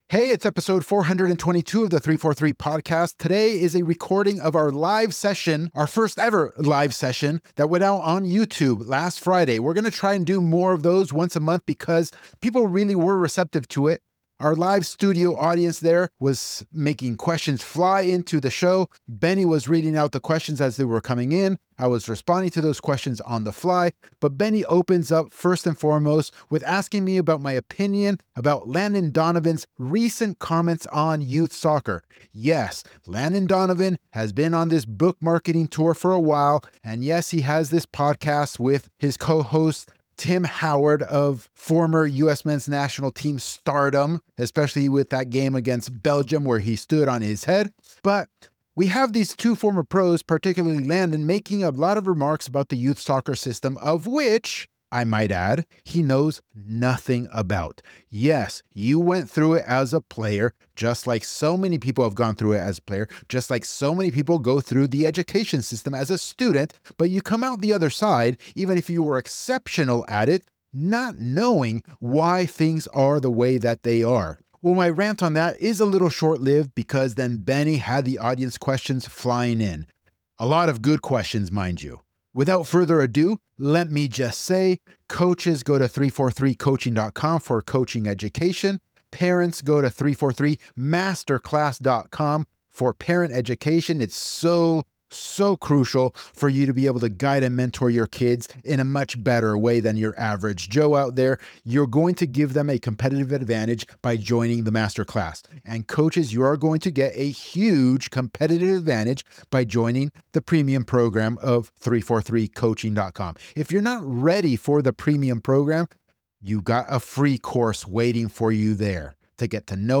Live Q&A.
We just dropped our first-ever live show, and the questions came flying in.